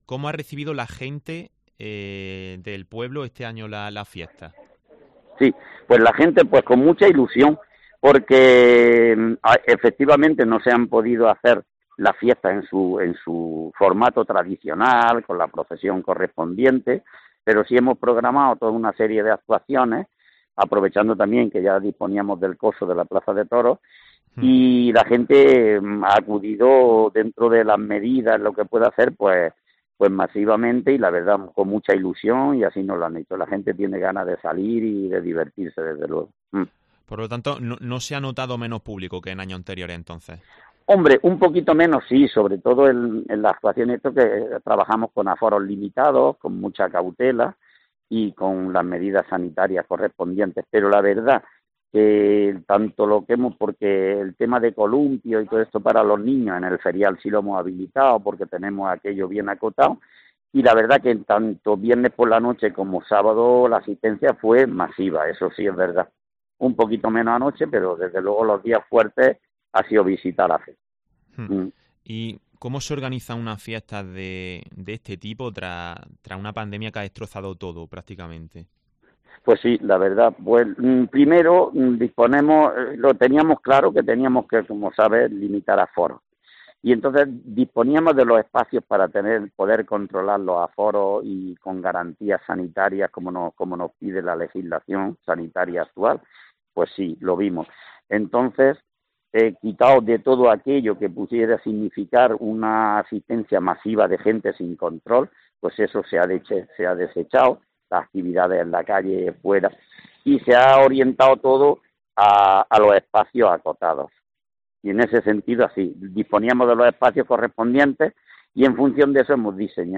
En COPE Granada, hemos podido hablar con Pedro Martínez, alcalde de Atarfe donde nos ha explicado cómo han afrontado este año la realización de este gran evento.